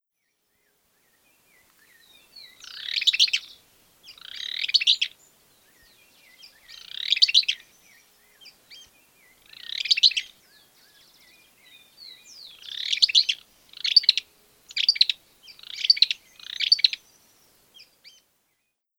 Euscarthmus meloryphus - Barullero
Euscarthmus meloryphus.wav